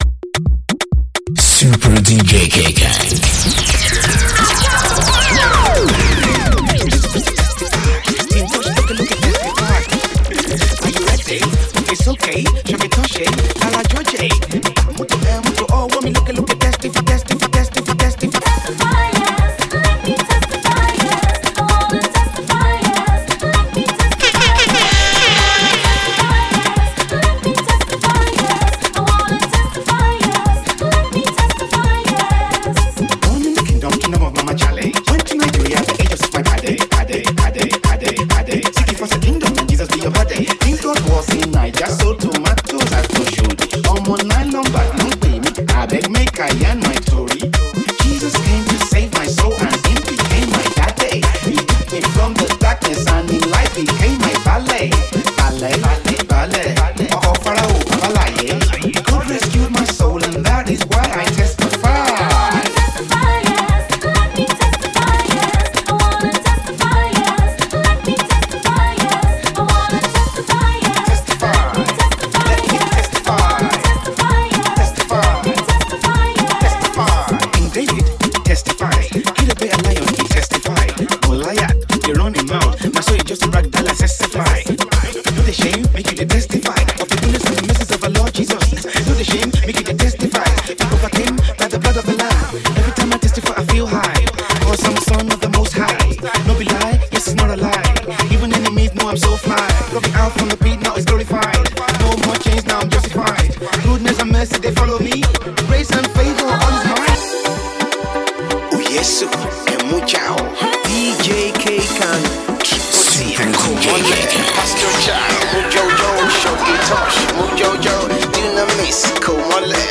The sizzling hot banging song